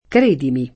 kr%dimi] — elis.: E il naufragar m’è dolce in questo mare [